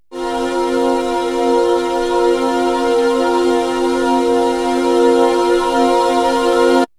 VOX_CHORAL_0003.wav